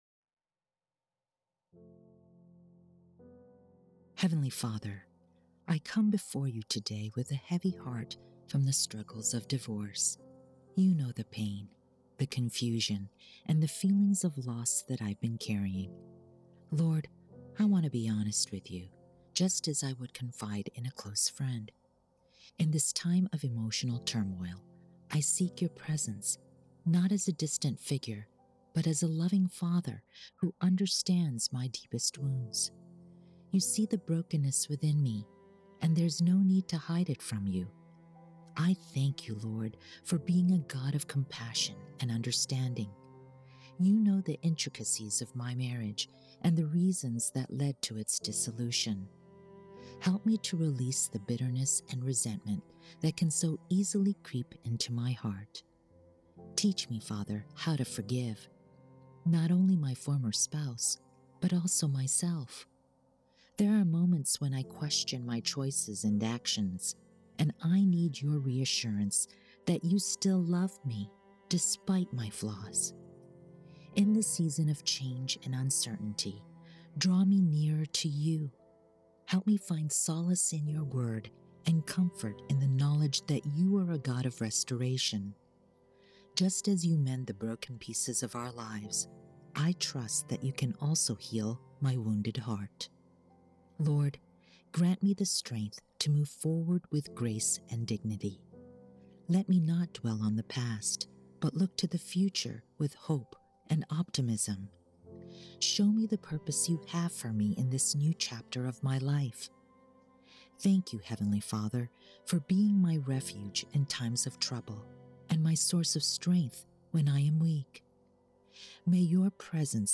EBA_Prayers_Divorced_Music_PreFinal-1.wav